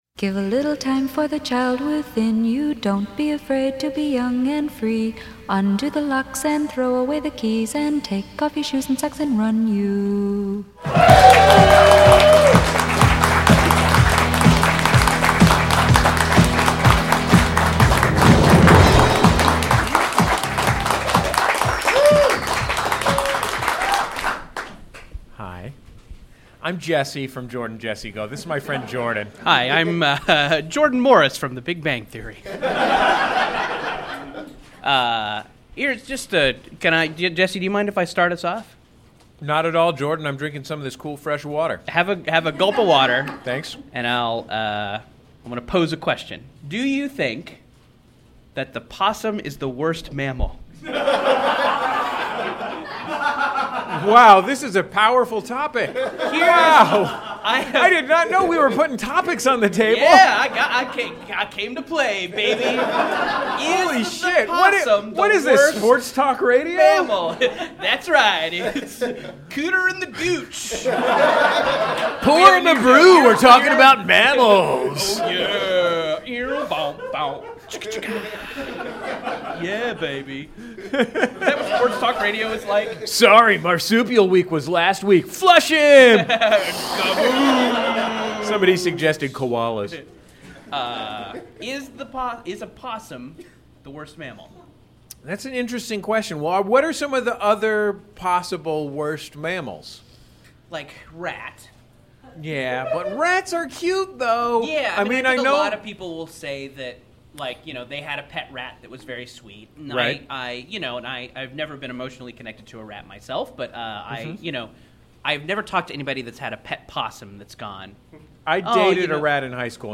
Ep. 421: Live at MaxFunHQ for MaxFunDrive 2016
Society & Culture, Comedy, Tv & Film